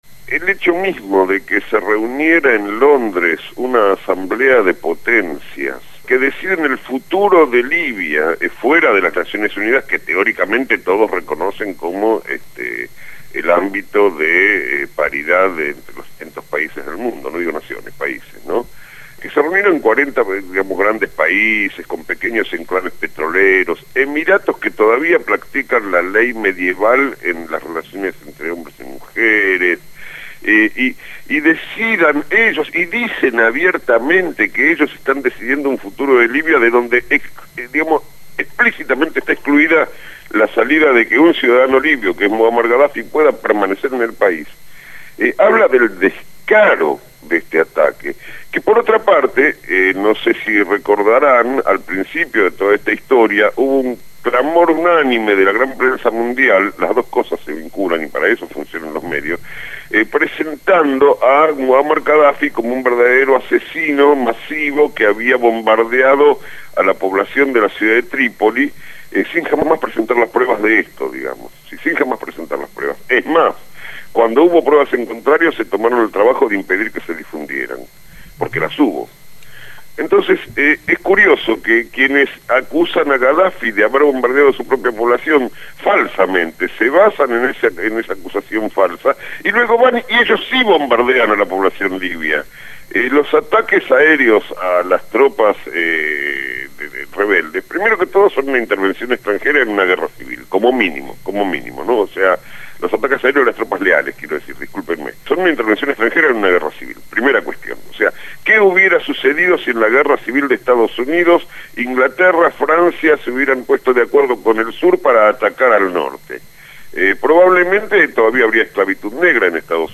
analista internacional, analiza los sucesos en Libia en el programa «Desde el barrio» (Lunes a viernes de 9 a 12 horas) por Radio Gráfica.